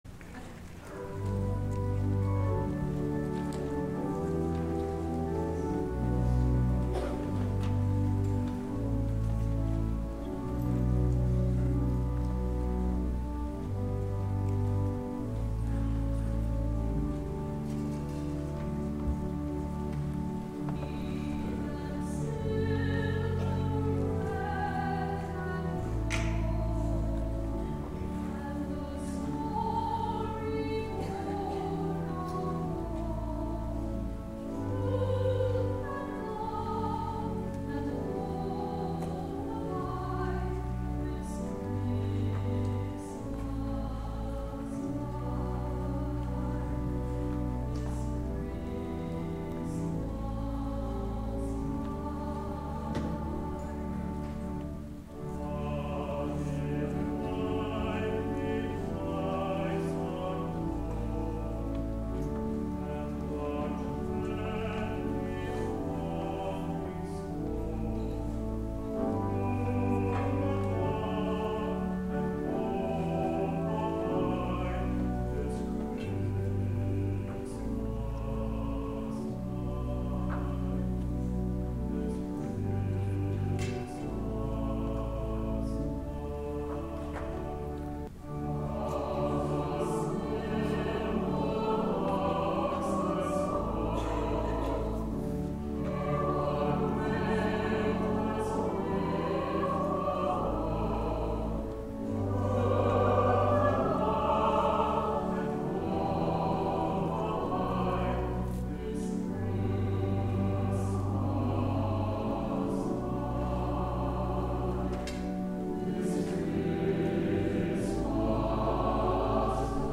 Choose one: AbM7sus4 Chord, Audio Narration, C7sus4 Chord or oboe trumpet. oboe trumpet